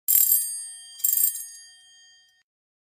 Sound Effects
Doorbell 3sec